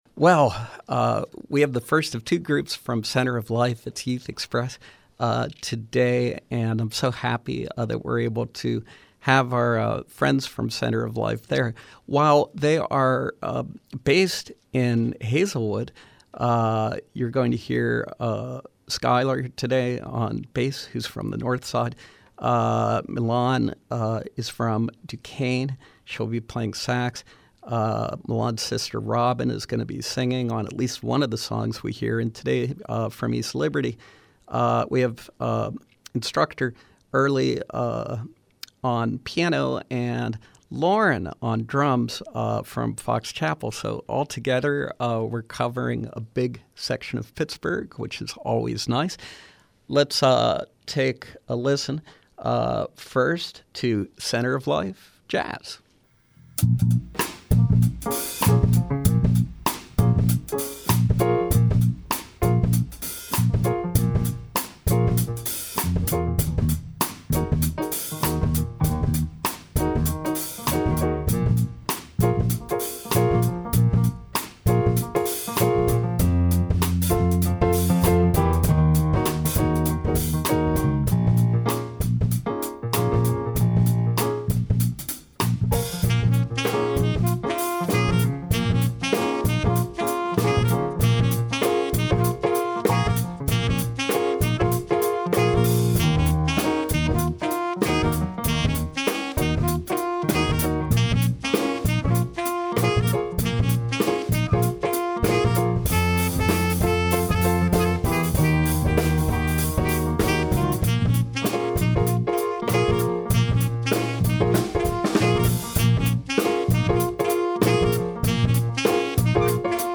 sax
bass
piano
drums